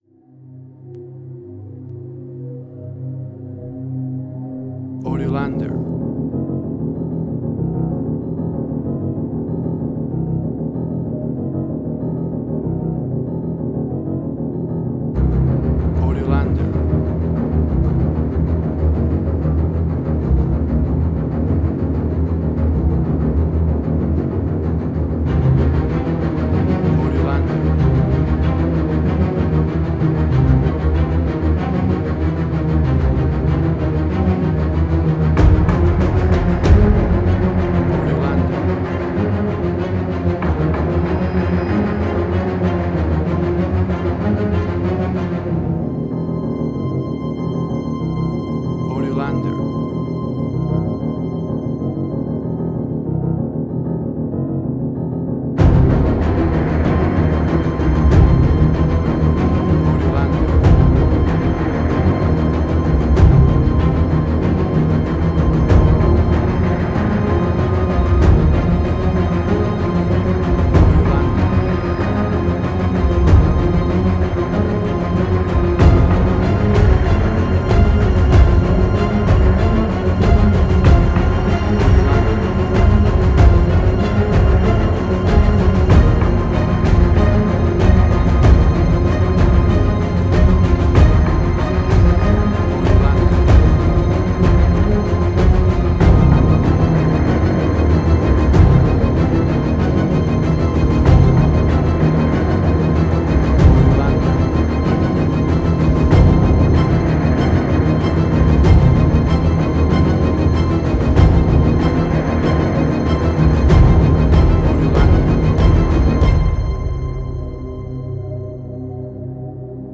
Modern Film Noir.
Tempo (BPM): 95